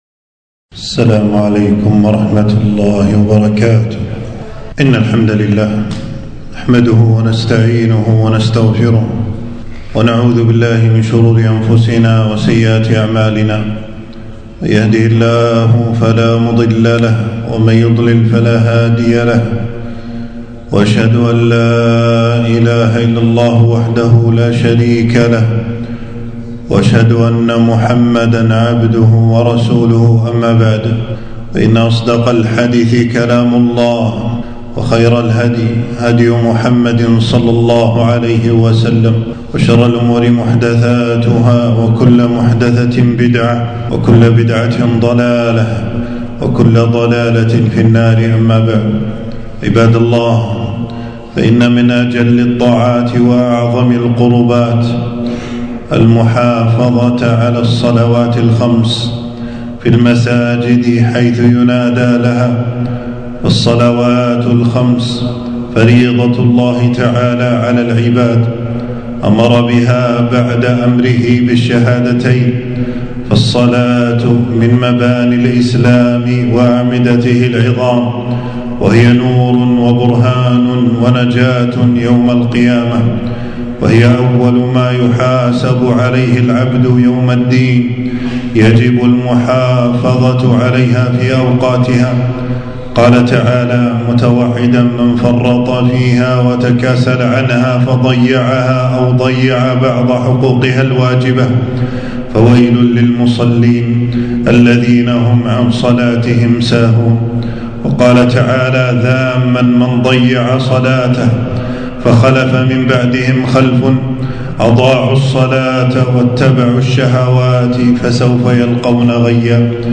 تنزيل تنزيل التفريغ خطبة بعنوان: فويل للمصلين الذين هم عن صلاتهم ساهون .
حفظه الله تعالى المكان: خطبة في يوم 20 ربيع الأول 1447هـ في مسجد السعيدي بالجهرا.